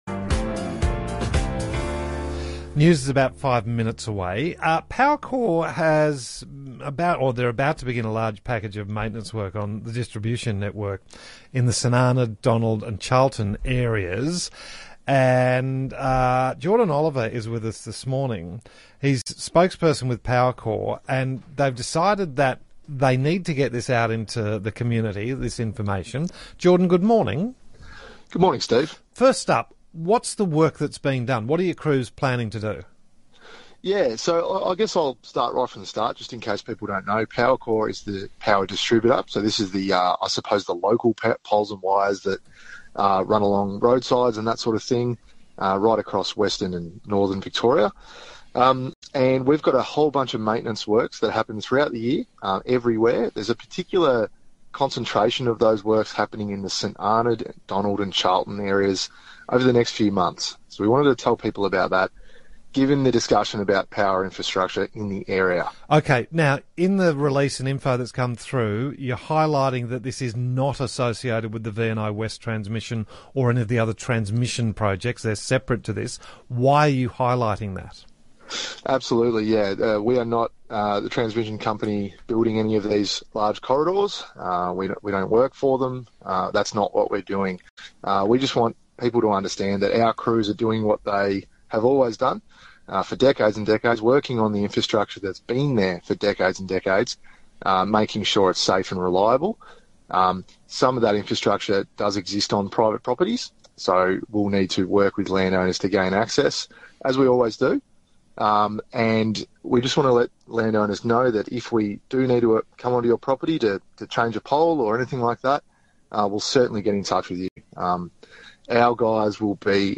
ABC-Ballarat-Powercor-explain-works.mp3